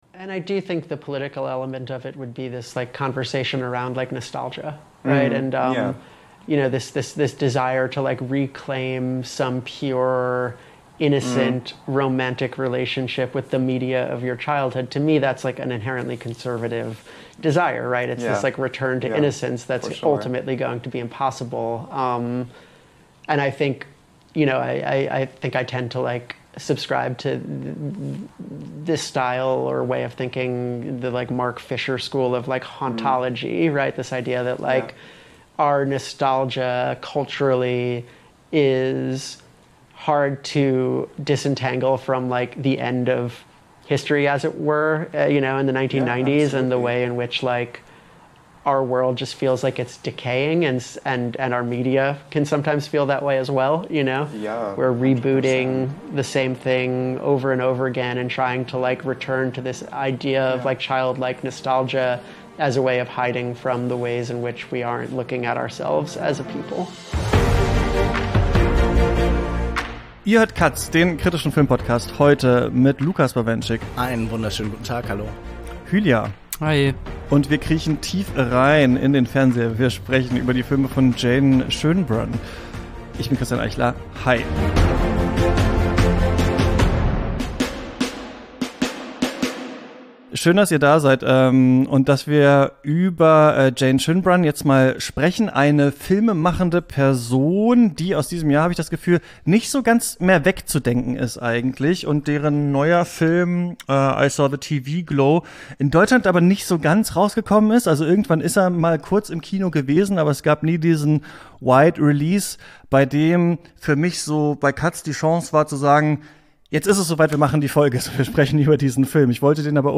1 #127: Solo Talk – Willkommen im Jahr 2025: Wohin geht deine Reise? 24:14